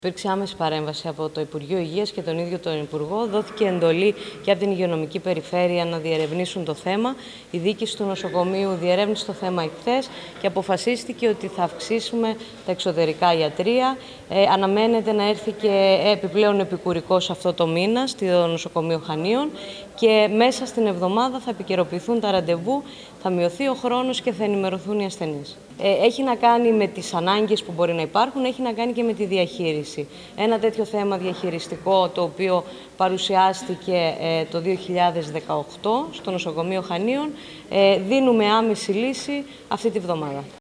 Σήμερα η διοικήτρια της ΔΥΠΕ Κρήτης κ. Λένα Μπορμπουδάκη αναφέρθηκε με δηλώσεις της στο θέμα: